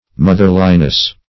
Motherliness \Moth"er*li*ness\, n.